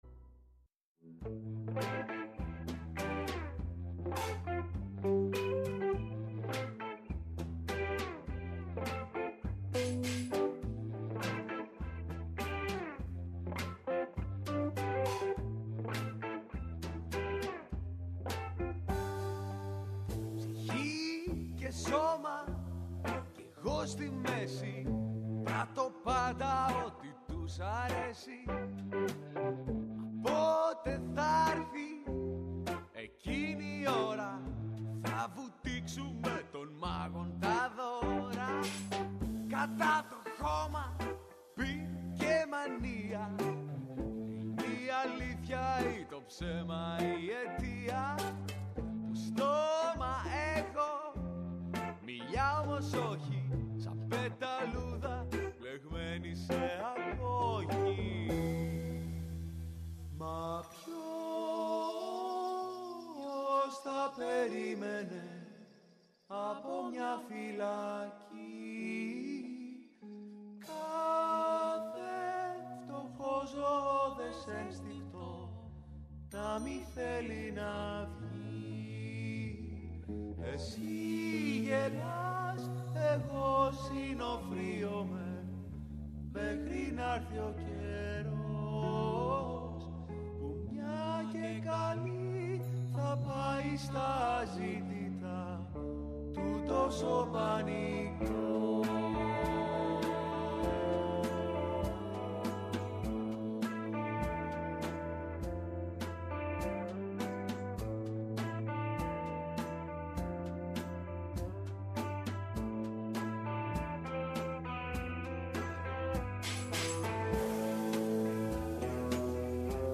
Στην εκπομπή οι AbOvo μίλησαν για το πώς και πότε σχημάτισαν την ομάδα τους, αλλά και για την παράσταση “Μυγοφαές” που παίζεται τώρα στο Θέατρο του Νέου Κόσμου, ενώ ακούστηκαν τραγούδια από προηγούμενες παραστάσεις της ομάδας.